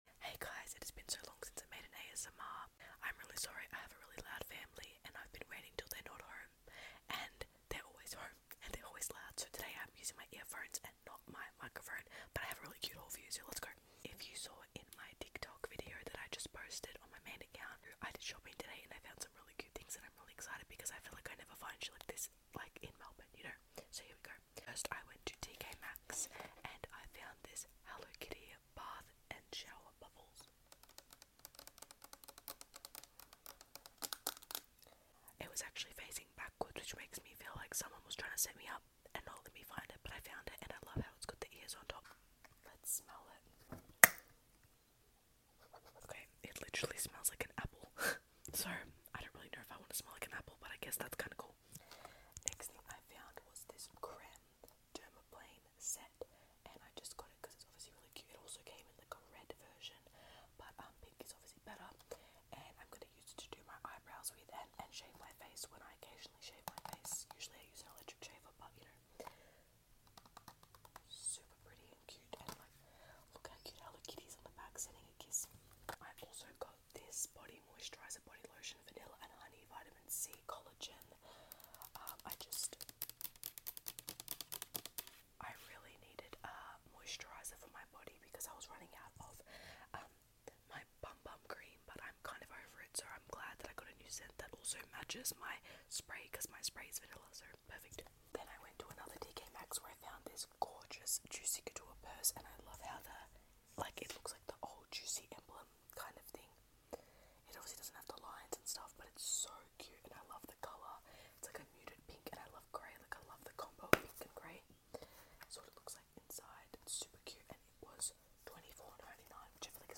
ASMR super cute shopping haul, sound effects free download